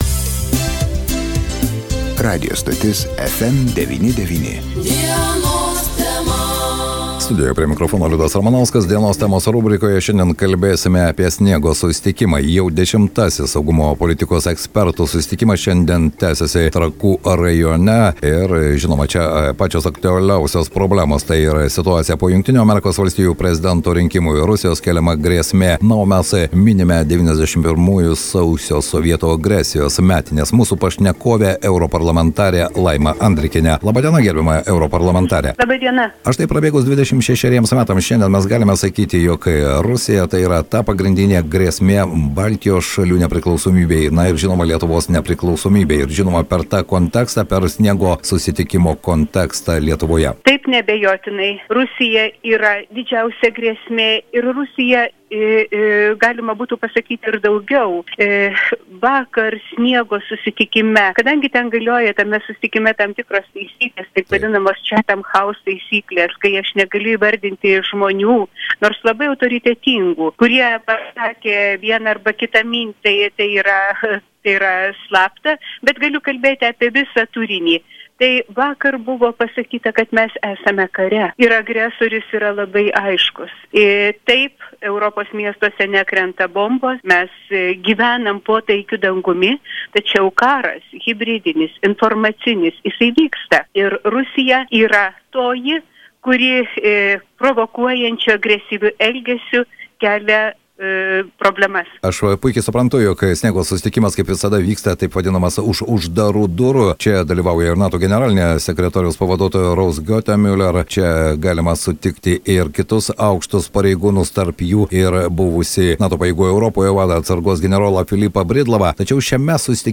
pokalbis su Europos Parlamento nare Laima Andrikiene.